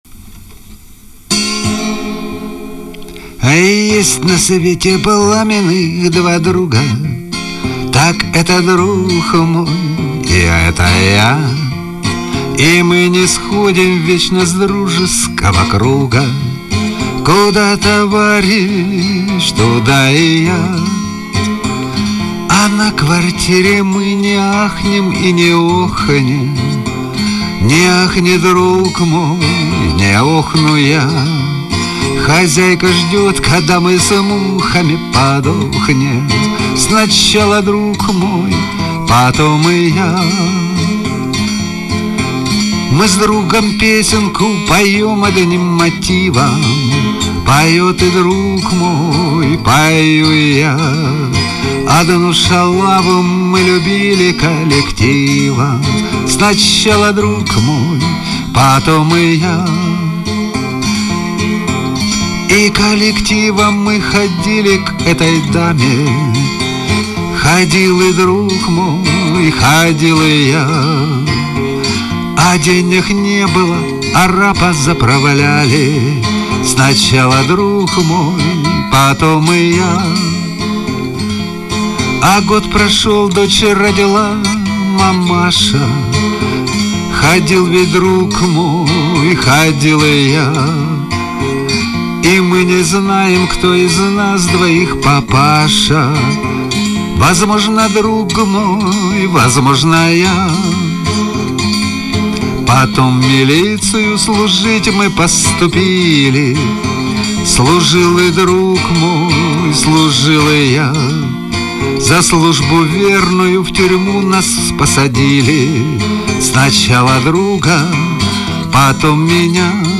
Пара дворовых варианта